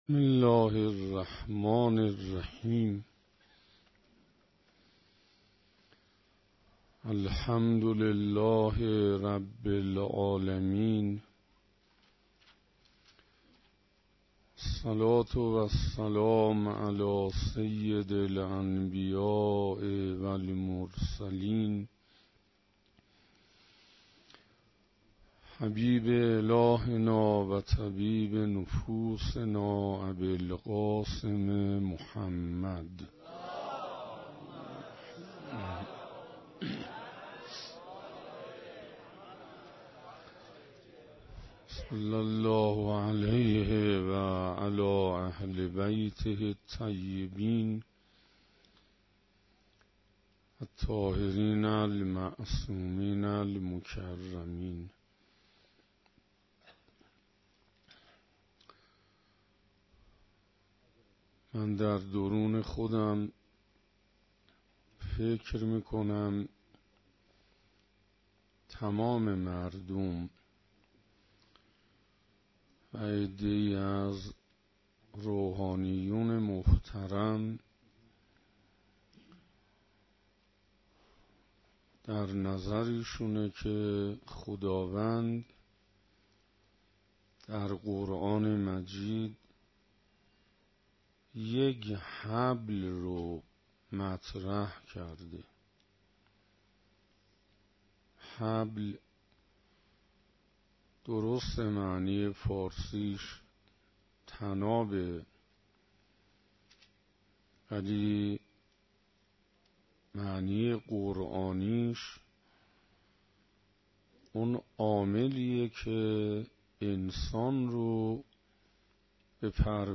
حسینیه آیت‌الله ابن‌الرضا - ربیع‌الثانی 96 - سخنرانی دوم